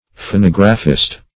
\Pho*nog"ra*phist\
phonographist.mp3